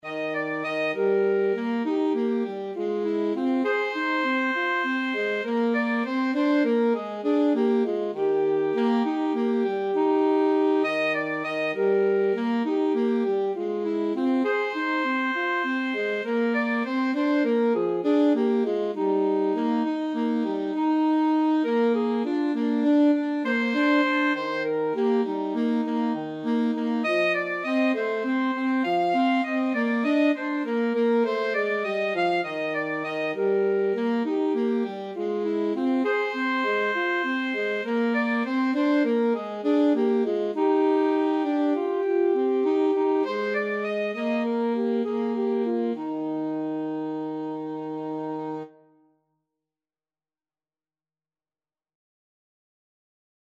9/8 (View more 9/8 Music)
Moderato